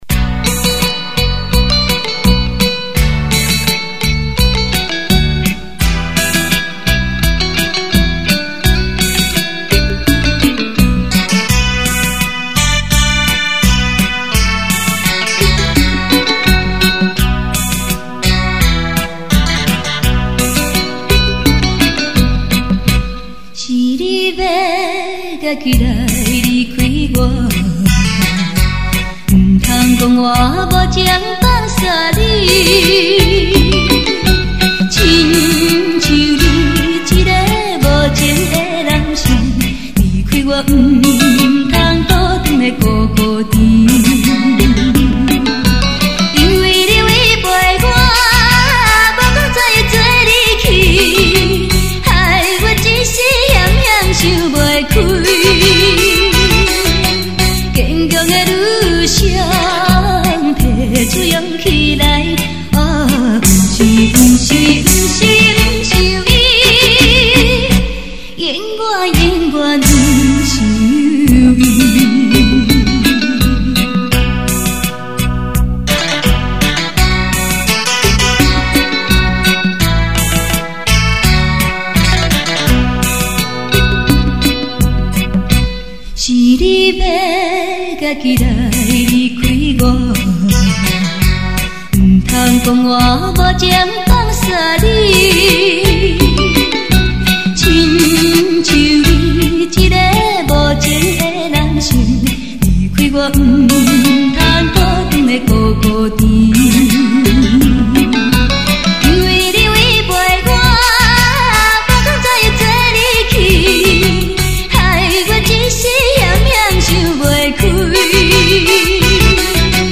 伴奏旋律不错